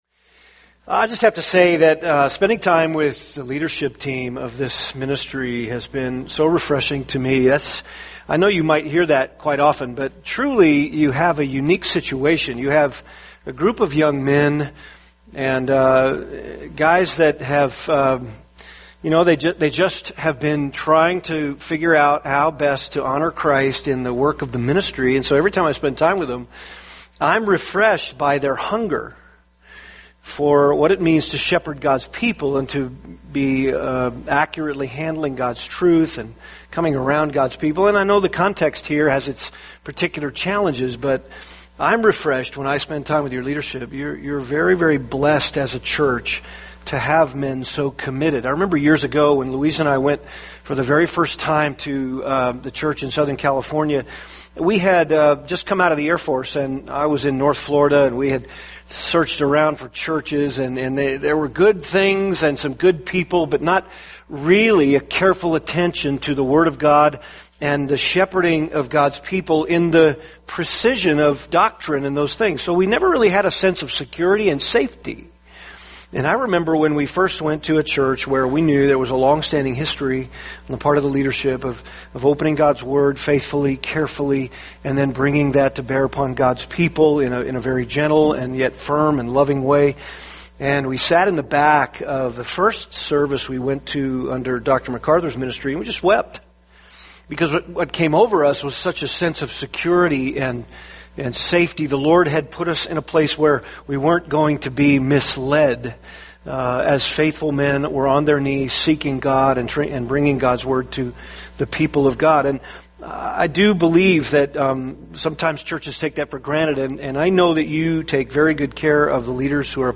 [sermon] Genesis 22:1-18 – Faith Refined to Gold | Cornerstone Church - Jackson Hole